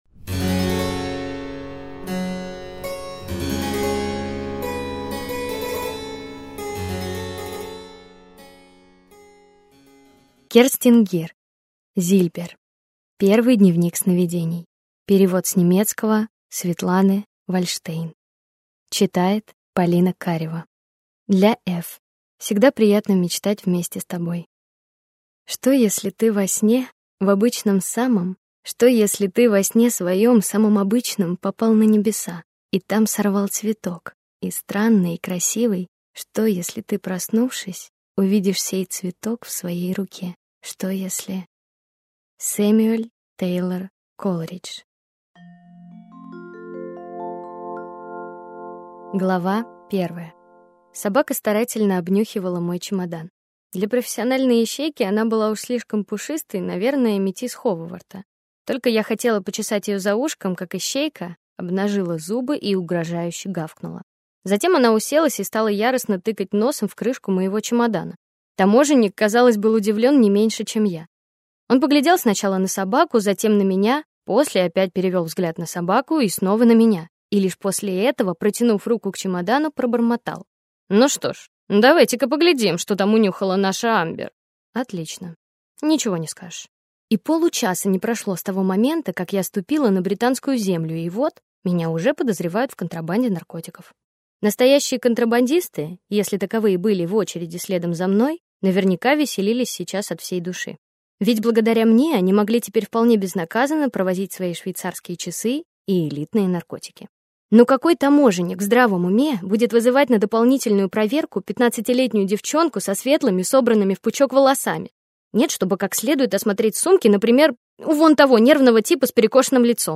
Аудиокнига Зильбер. Первый дневник сновидений | Библиотека аудиокниг